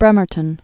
(brĕmər-tən)